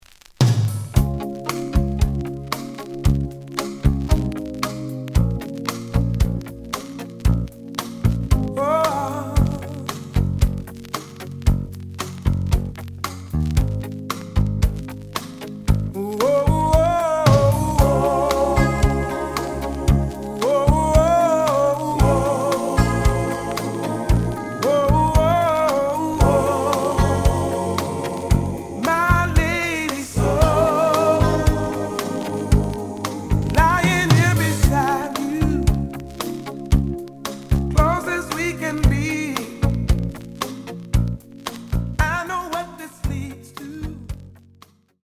The audio sample is recorded from the actual item.
●Genre: Soul, 80's / 90's Soul
Slight noise on both sides.